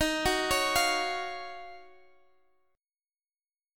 Listen to D#m9 strummed